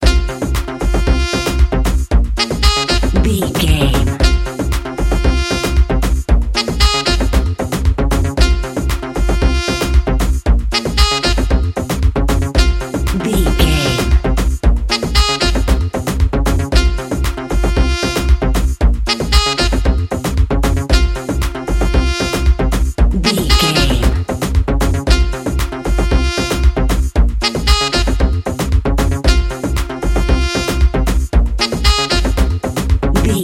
Aeolian/Minor
Fast
driving
energetic
high tech
uplifting
hypnotic
industrial
groovy
drum machine
synthesiser
saxophone
electronic
techno
trance
synth leads
synth bass